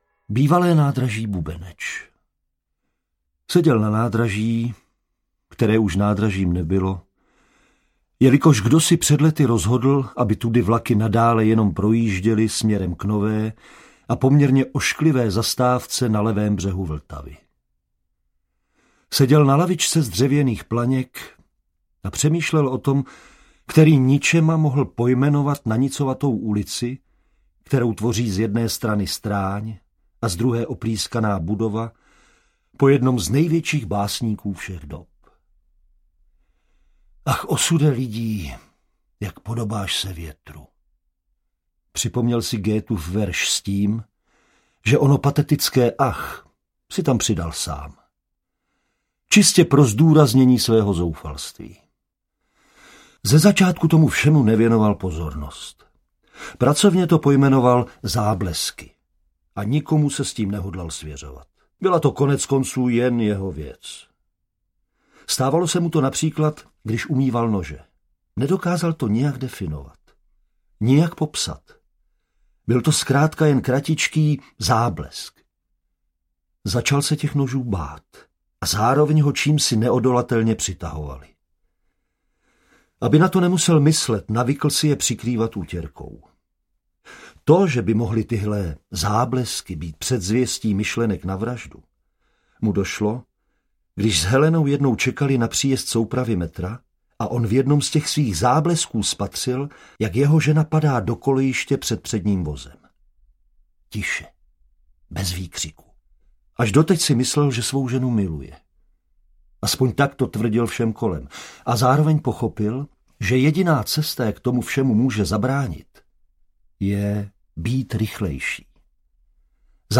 Audiokniha Praha NOIR - 14 povídek předních českých spisovatelů v podání 14 výtečných českých herců
Ukázka z knihy
• InterpretDavid Novotný, Lukáš Hlavica, Pavla Beretová, Hana Maciuchová, Jan Vlasák, Martina Hudečková, Jan Vondráček, Martin Myšička, Alexej Pyško, Hynek Čermák, Viktor Dvořák, Petr Stach, Jaromír Dulava